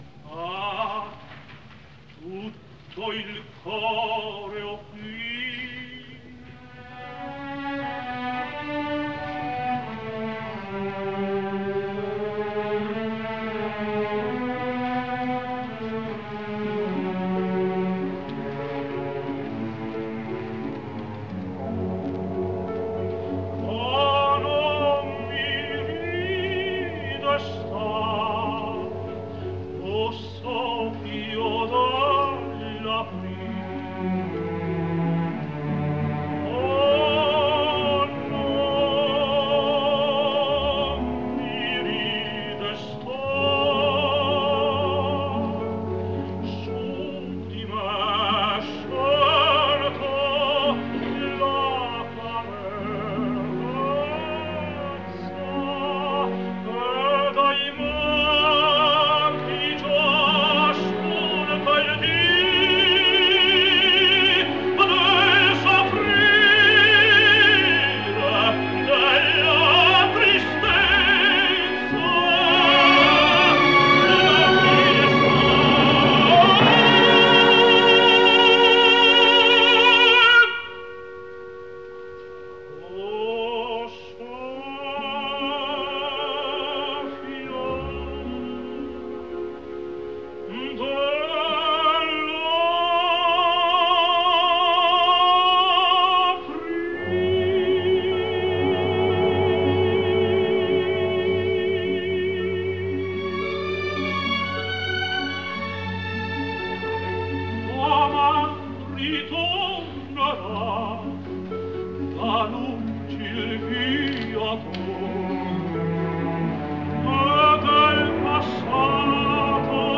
Werther - Ah! non mi ridestar (Carlo Bergonzi. Napoli, February 13, 1969)